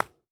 Golf Hit Putt.wav